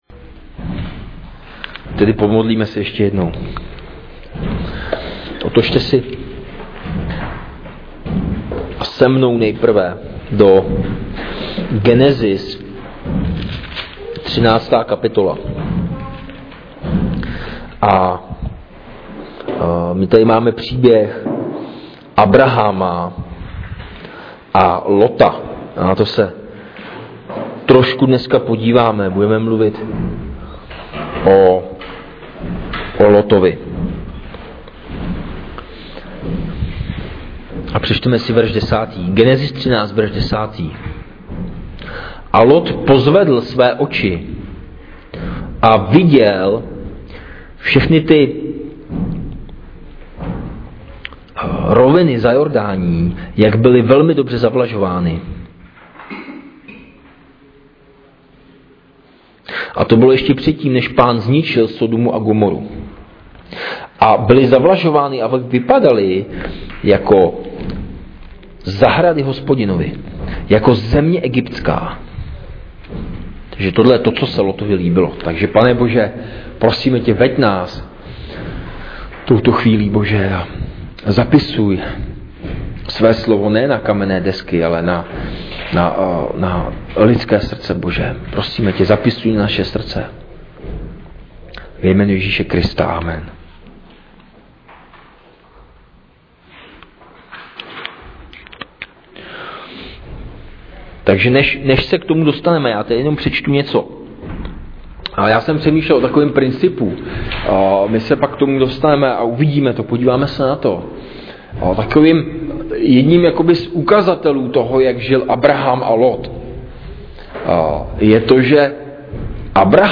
Ze série 1.list Janův (nedělní bohoslužba)